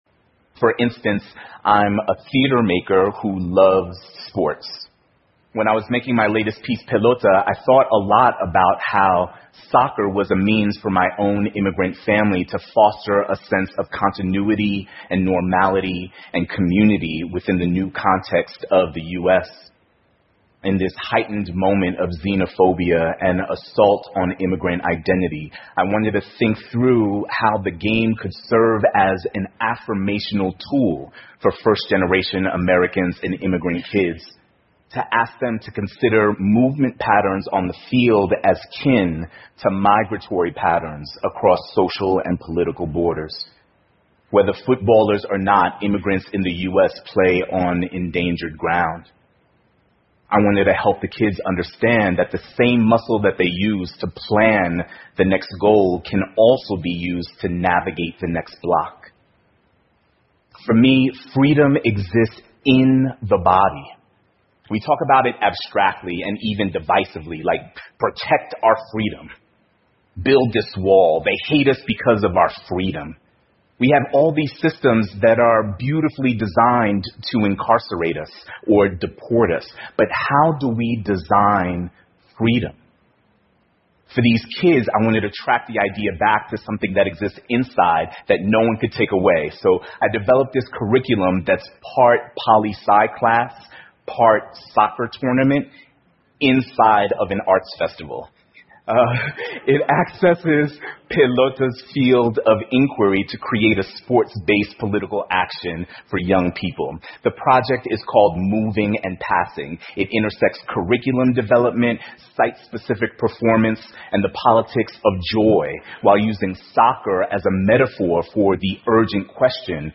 TED演讲:足球能教会我们什么是自由() 听力文件下载—在线英语听力室